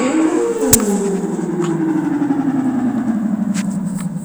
Space Echo Sound Effect Free Download
Space Echo